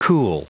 Prononciation du mot cool en anglais (fichier audio)
Prononciation du mot : cool